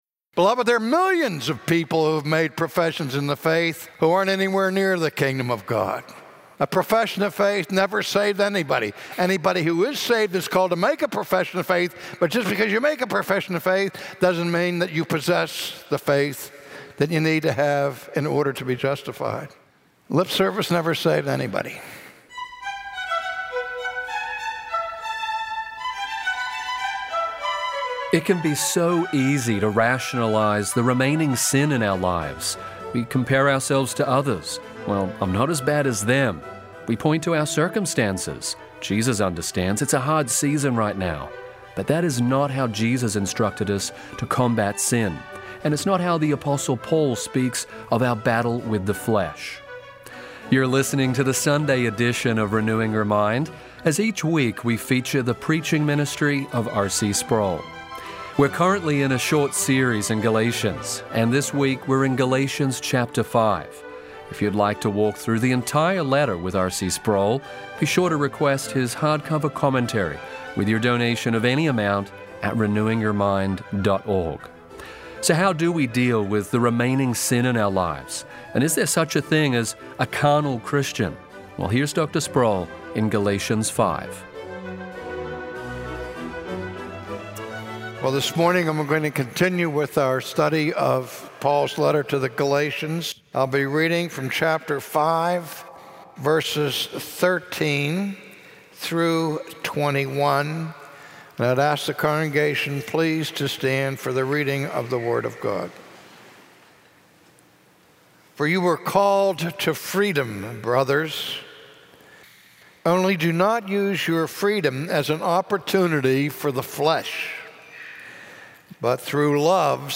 Christians are no longer under the dominion of sin. As we grow in sanctification, we must put to death the old desires of the flesh. From his sermon series in the book of Galatians, today R.C. Sproul exhorts us to examine our lives and pursue holiness in the power of the Holy Spirit.